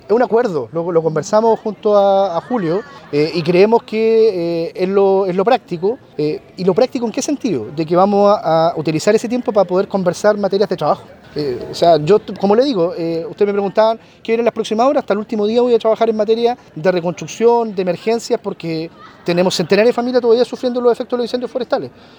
Por su parte, el delegado saliente, Eduardo Pacheco, indicó que esta decisión de no realizar una acto o ceremonia para el cambio de mando local, obedece a un acuerdo al que llegaron ambos.